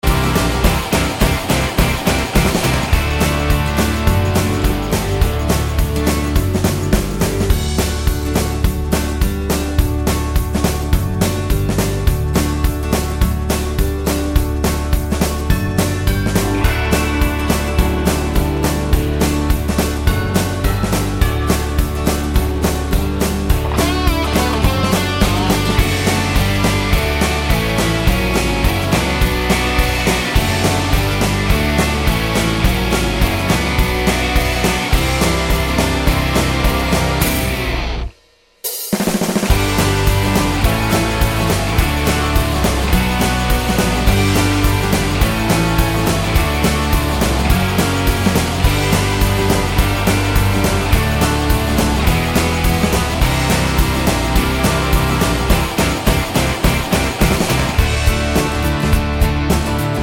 no Backing Vocals T.V. Themes 2:39 Buy £1.50